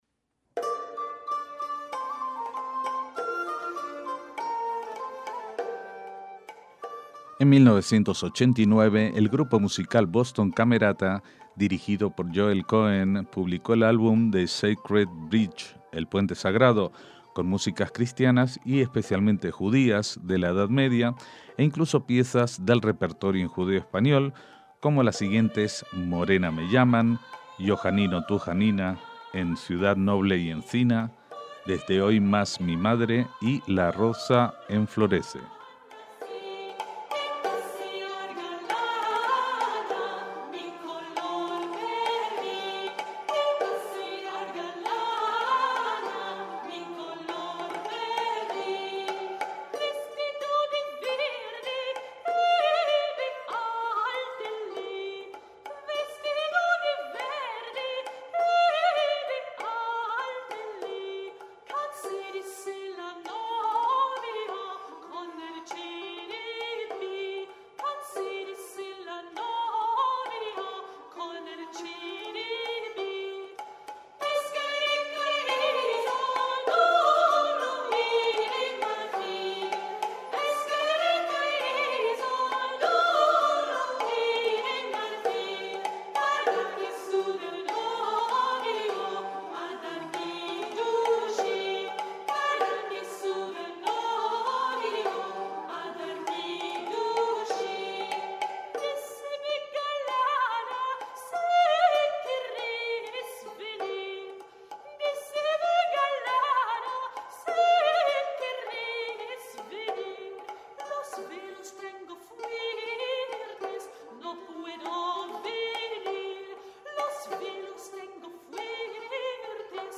MÚSICA SEFARDÍ
laúd soprano, laúd turco y percusión
viola medieval
laúd árabe
flautas
zanfona y dessus de viole
vielle